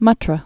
(mŭtrə)